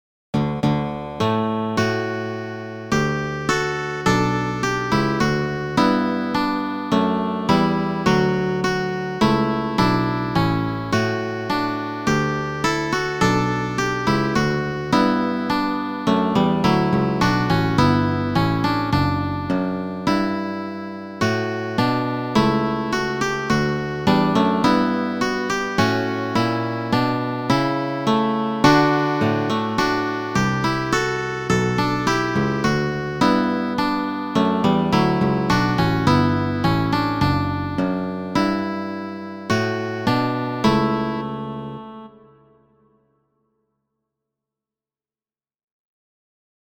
Muziko: